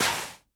Minecraft Version Minecraft Version 1.21.5 Latest Release | Latest Snapshot 1.21.5 / assets / minecraft / sounds / block / soul_sand / step4.ogg Compare With Compare With Latest Release | Latest Snapshot